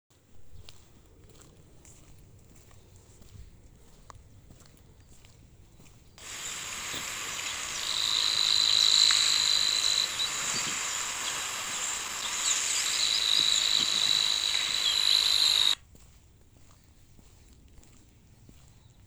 Birds -> Warblers ->
Savi’s Warbler, Locustella luscinioides
StatusVoice, calls heard